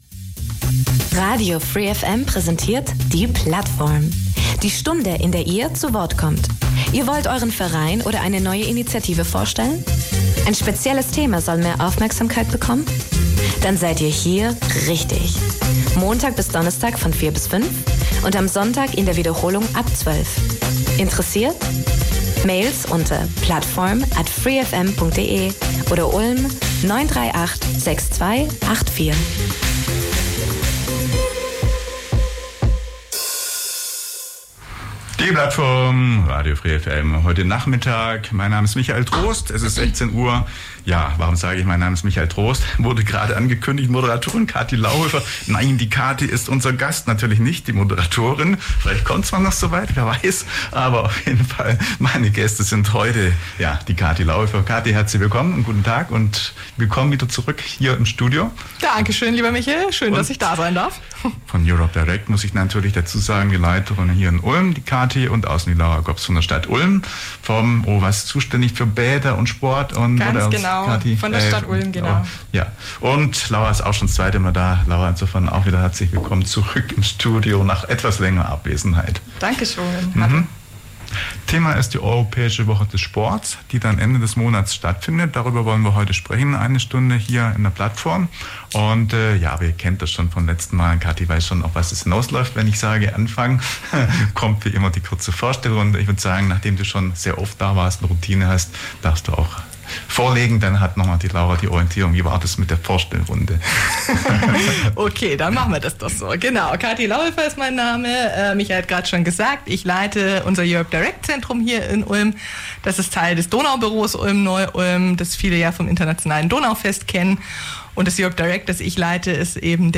Interview bei Radio Free FM